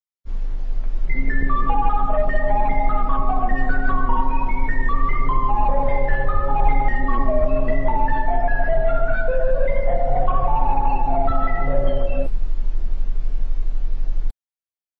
South-korea-eas-alarm-(1950)-made-with-voicemod Sound Effect Download: Instant Soundboard Button